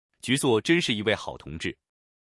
文字转语音
这是一款免费的文本转语音工具，提供语音合成服务，支持多种语言，包括中文、英语、日语、韩语、法语、德语、西班牙语、阿拉伯语等74种语言，318种声音。